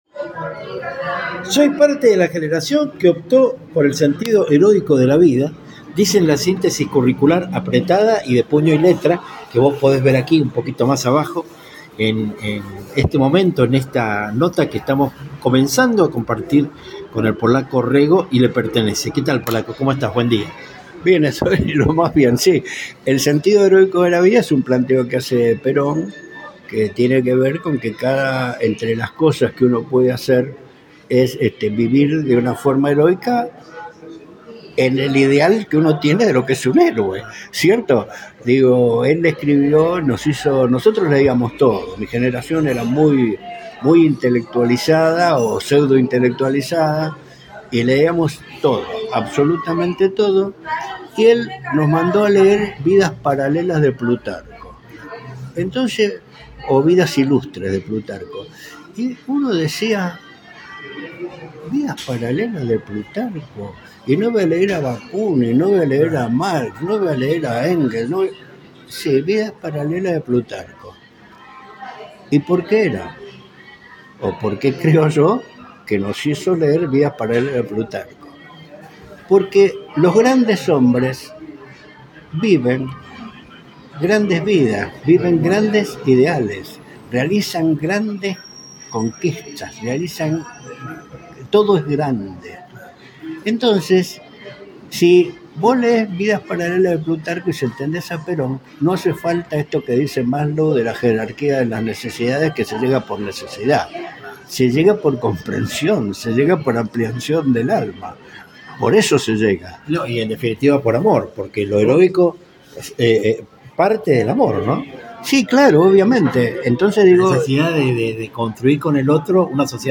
Entrevistas militantes
Vas a escuchar un delicioso diálogo que quizás obre de puntapié inicial de un sinnúmero de conversaciones en el mismo sentido.